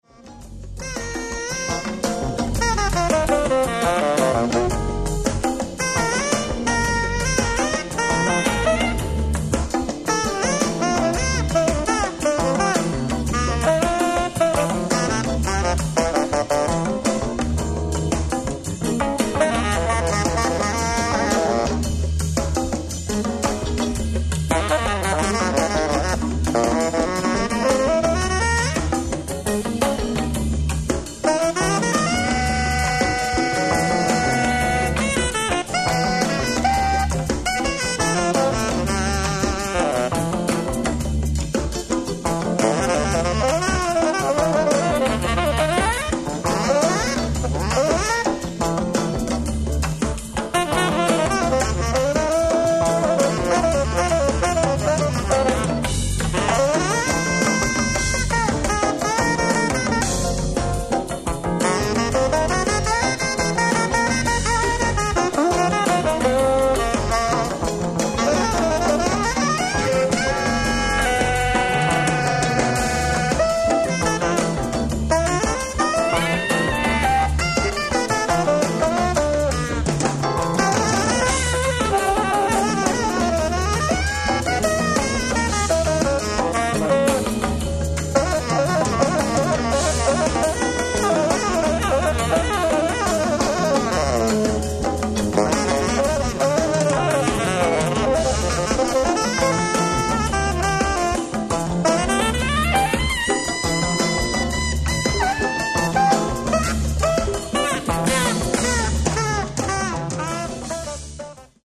ライブ・アット・クィーン・エリザベス・ホール、ロンドン 01/17/2000
※試聴用に実際より音質を落としています。